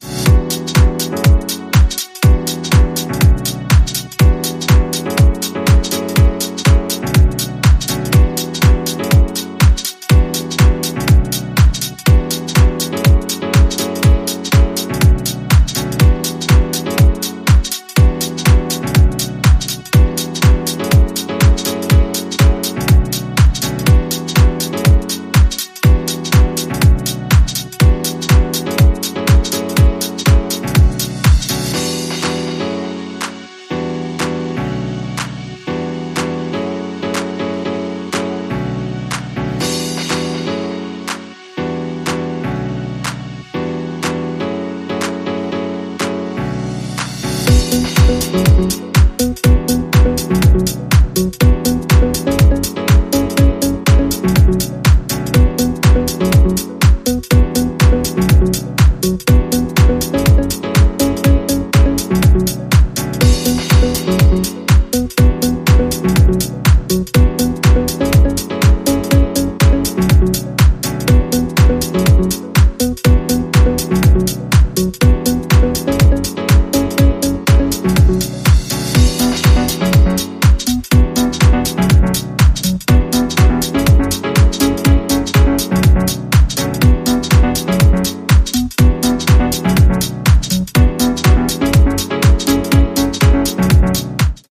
ここでも、今までのように程よくエレクトリックな音色を用いながらモダンでウォームなディープ・ハウスを展開。